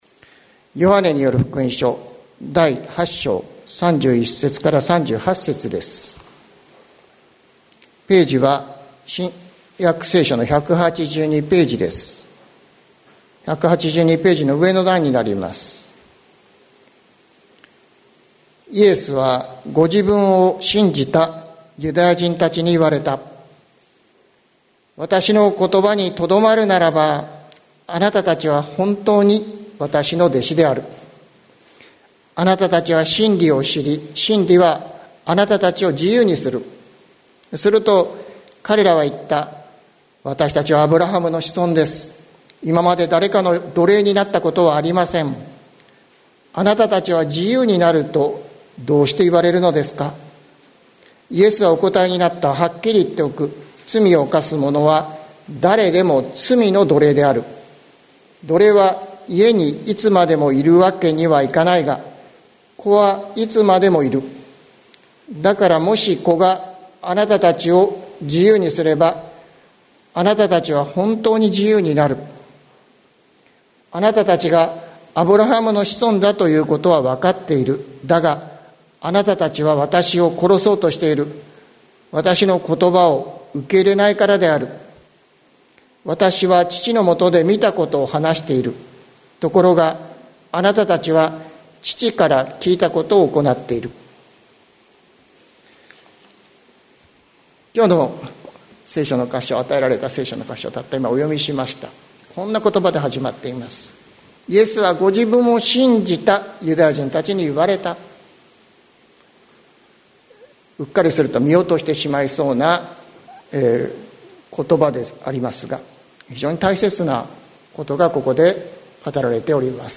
2022年08月14日朝の礼拝「自由が欲しい人のために」関キリスト教会
説教アーカイブ。